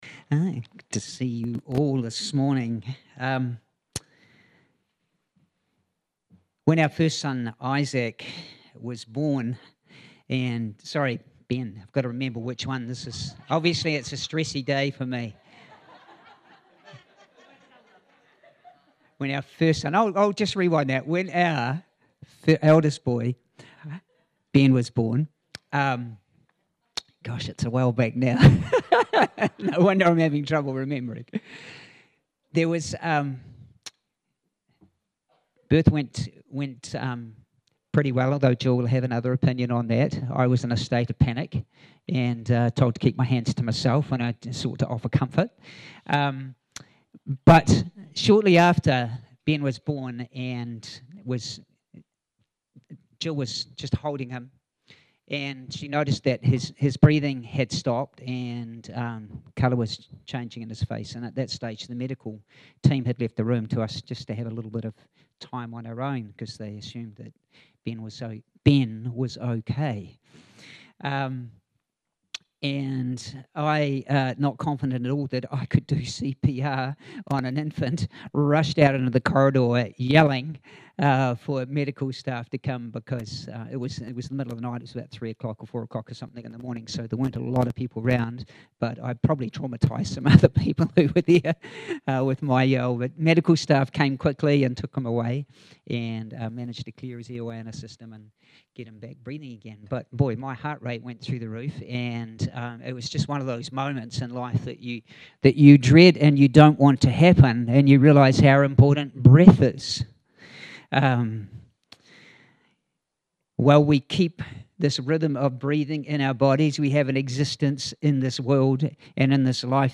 The Breath of Life – A Pentecost Sermon